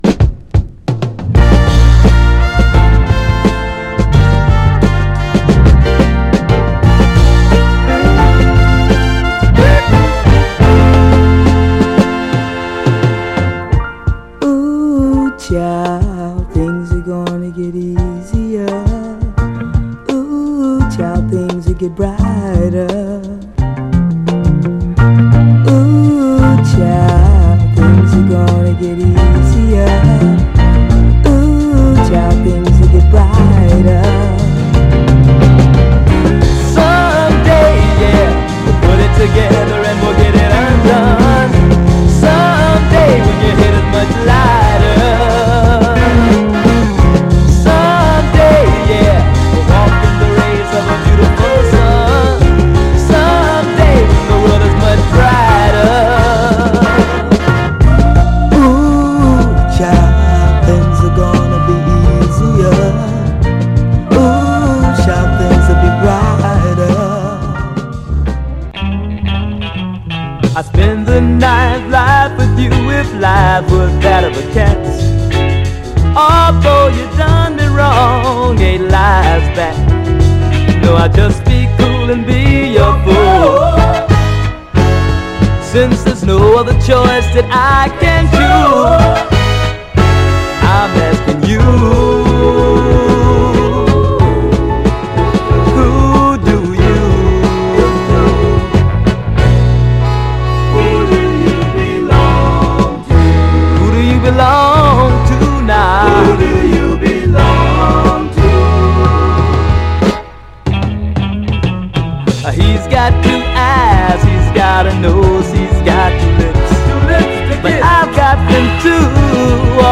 盤はごく薄いスレ、A面エッジに目立つペーパーマーク箇所ありますが、音への影響は少なくプレイ概ね良好です。
※試聴音源は実際にお送りする商品から録音したものです※